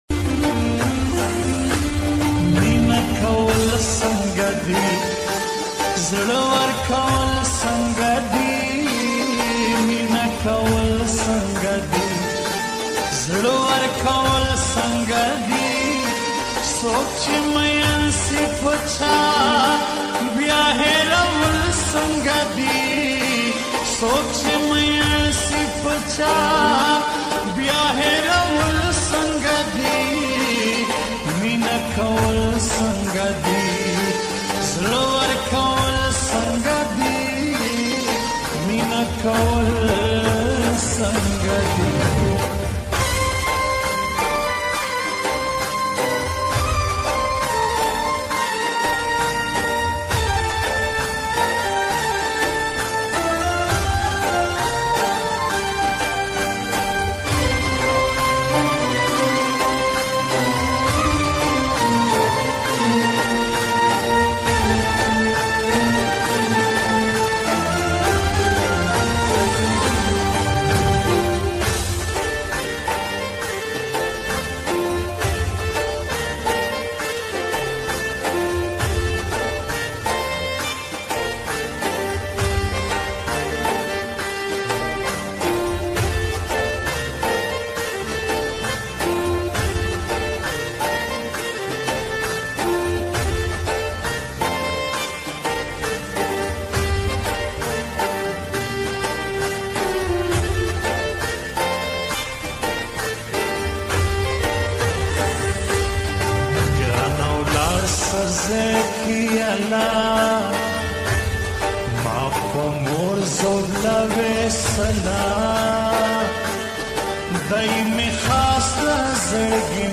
𝙨𝙡𝙤𝙬𝙚𝙙+𝙍𝙚𝙫𝙚𝙧𝙗 𝙨𝙖𝙙 𝙨𝙤𝙣𝙜